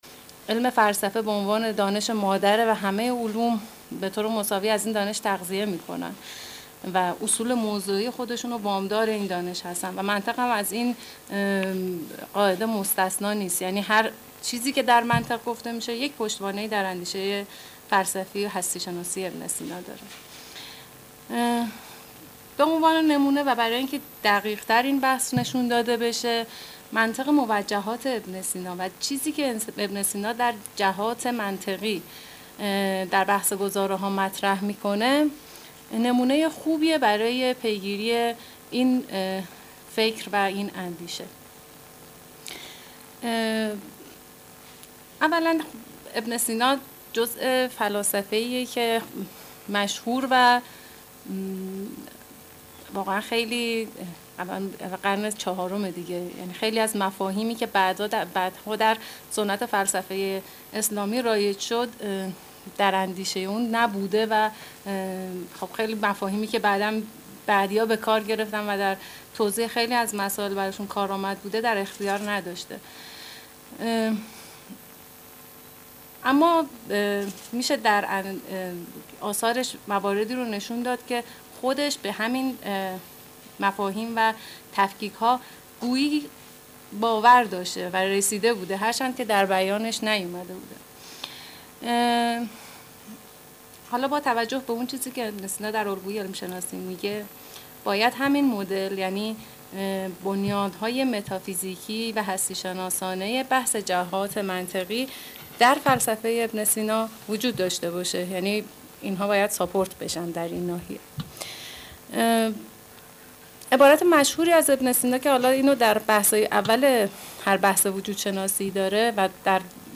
سمینار علمی منطق در تفکر اسلامی